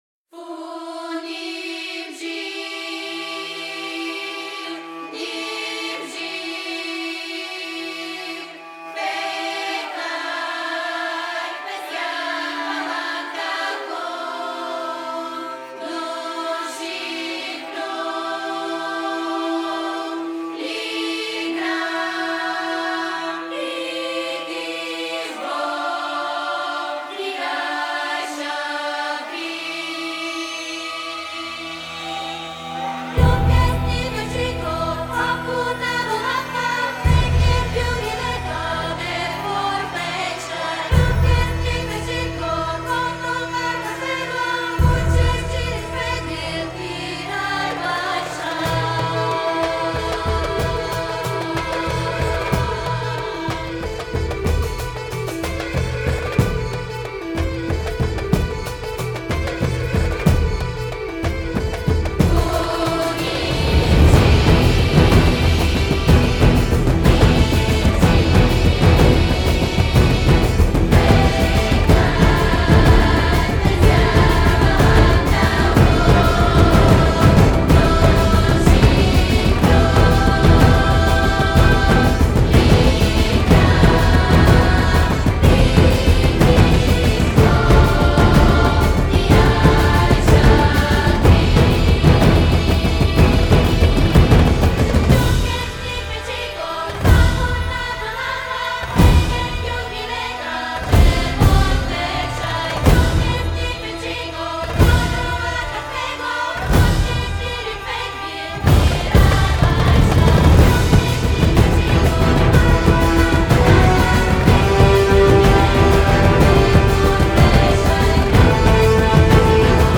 Puissant.